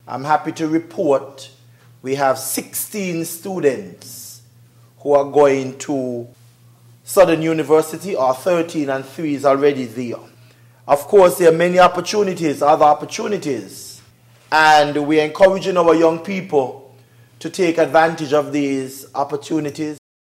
On August 11th, 2025, Deputy Prime Minister and Minister of Education Dr. Geoffrey Hanley gave this update: